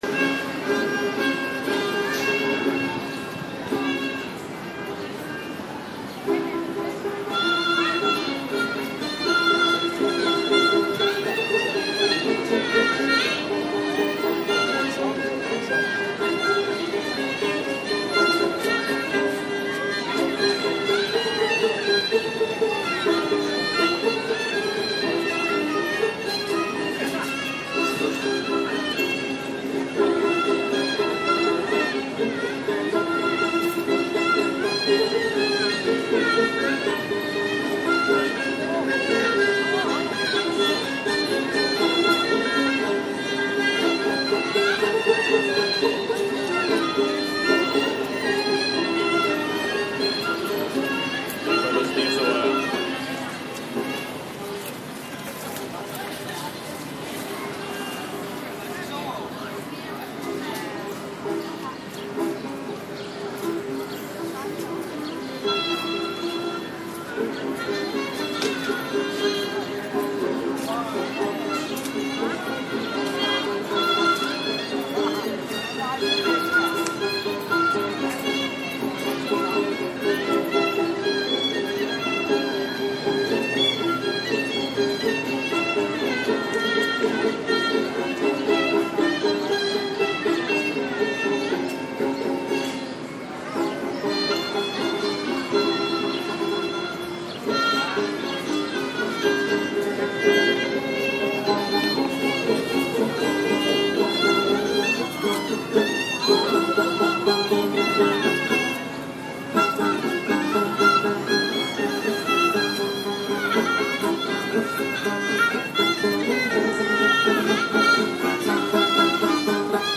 Columbus Park Sounds
Columbus Park, Lower Manhattan
[…] to upload his own mp3 files of atmospheric recordings from his travels, as displayed in posts like “Columbus Park Sounds.”